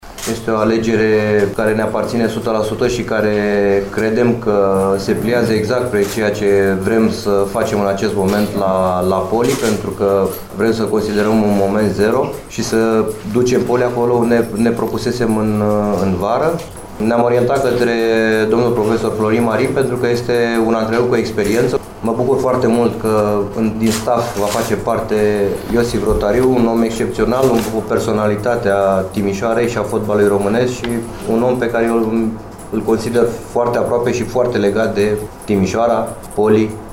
De altfel, o bună parte a conferinţei de presă pentru prezentarea noului antrenor a fost prilej de regrete faţă de încheierea “mandatului Alexa”.